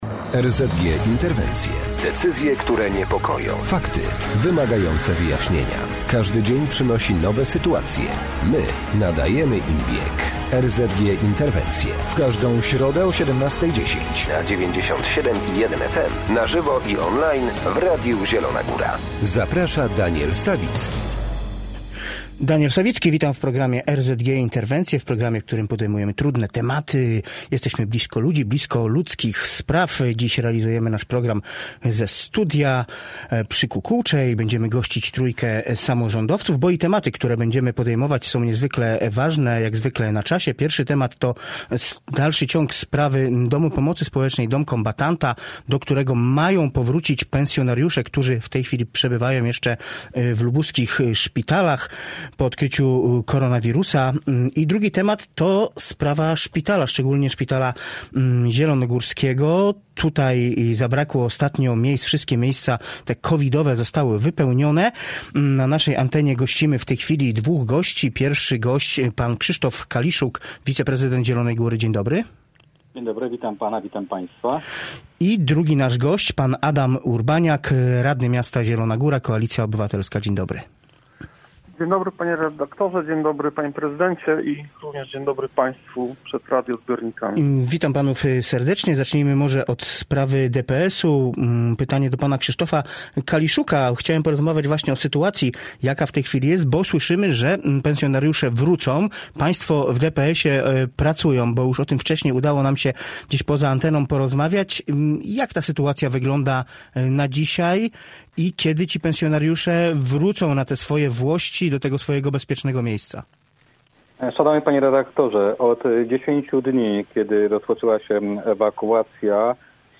Bożena Ronowicz – radna miasta Zielona Góra PiS